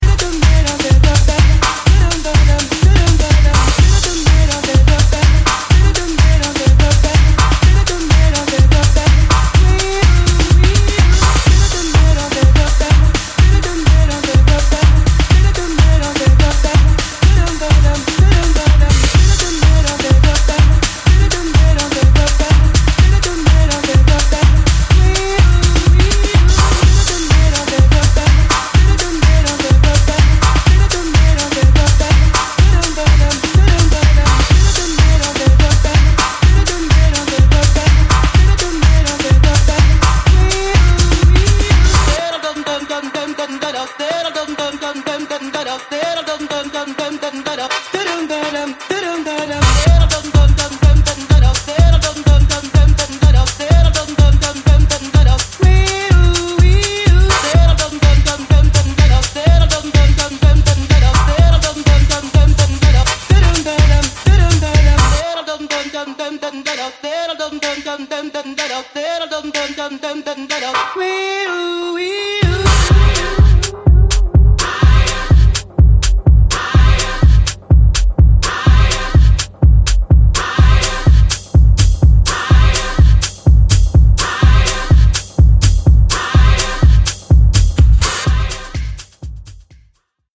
Mysterious 90��s old school house groovin.
HOUSE/BROKEN BEAT